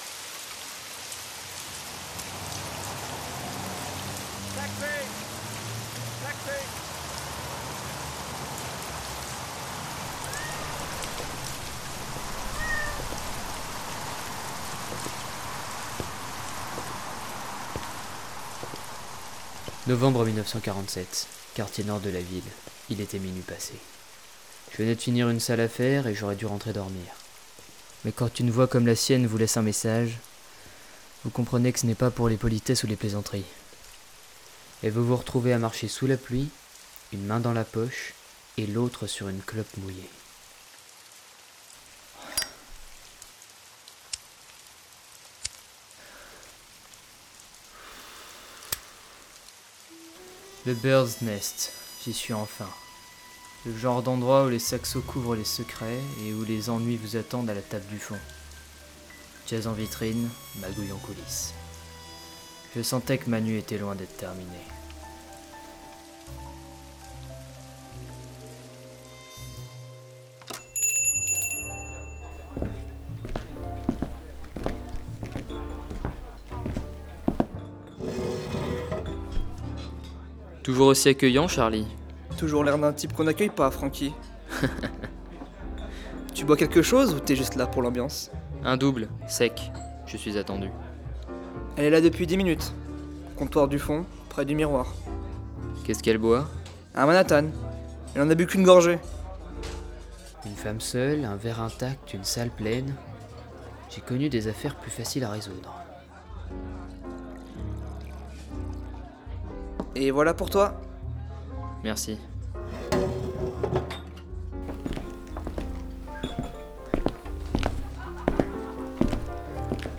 Where shadows whisper, and jazz drives the night.
As part of our sound editing course, we created an audio drama inspired by American film noir from the 1940s and 1950s. Entitled Trafic à Bacall’s Bay, our project follows a disillusioned detective investigating the rainy streets of an imaginary city amid smoky bars, jazz music and criminal intrigue.
To achieve this, we incorporated classic film noir elements such as interior monologue, incisive dialogue, mysterious women and a jazz soundtrack. We used a combination of sound effects from the BBC, Pixabay and Freesound, original recordings and MIDI music to create a coherent soundscape. Every detail, from the clink of a glass to the tone of a voice to the reverberation of a bar, contributes to the realism and dramatic tension.
We used Trello to share tasks and worked with tools such as Pro Tools, DaVinci Resolve and a Sennheiser cardioid microphone.